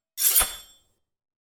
SwordSoundPack
SWORD_15.wav